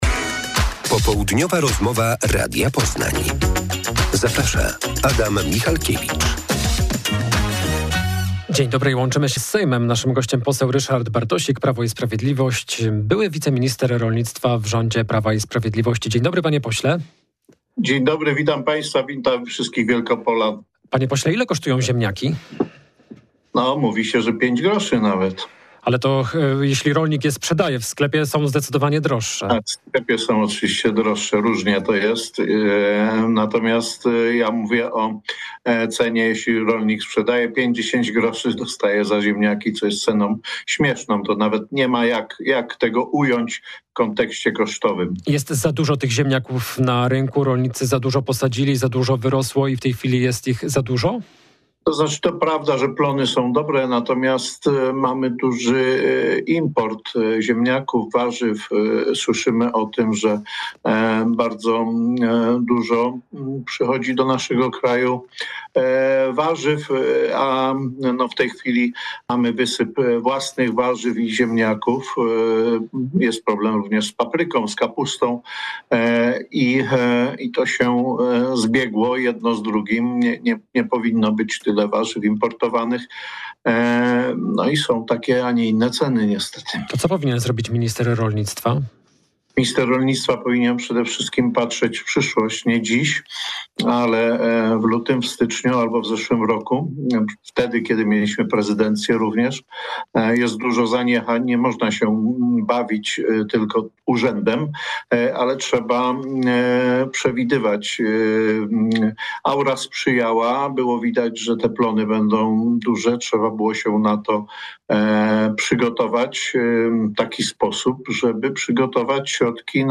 Popołudniowa rozmowa Radia Poznań – Ryszard Bartosik